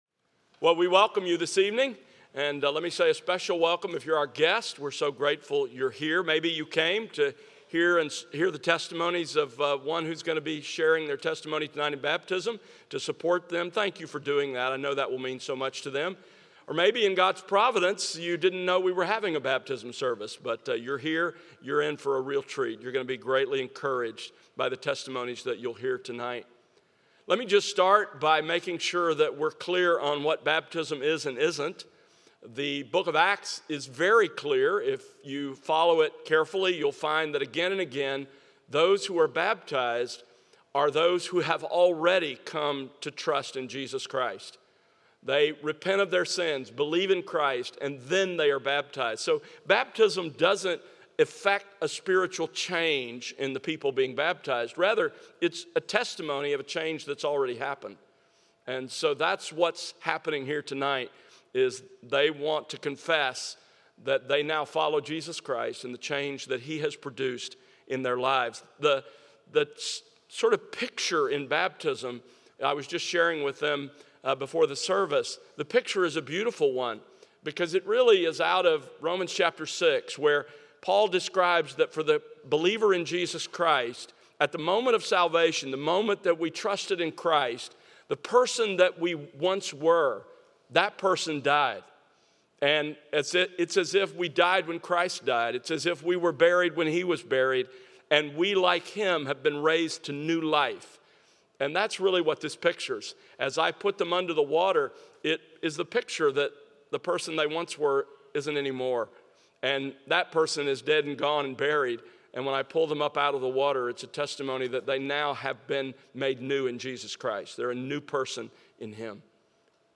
Baptisms